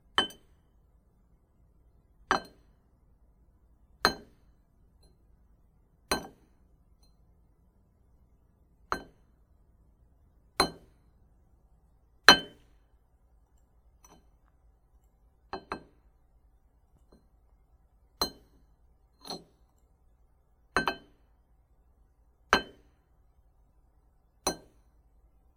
陶瓷板的声音
描述：吃饭时使用盘子的声音。
标签： 厨房 晚餐 餐具 勺子 陶瓷 瓷器 SET-下来 铮铮 叉子
声道立体声